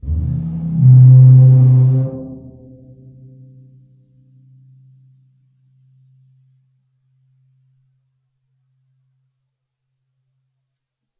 Percussion
bassdrum_rub2_v1.wav